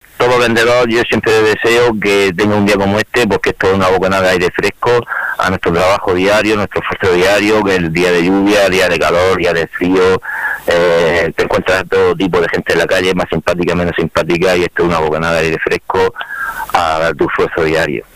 Un hombre emotivo y espontáneo, de voz cantarina.